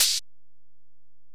Index of /kb6/Alesis_DM-PRO/HiHat
HiHat_Region_095.wav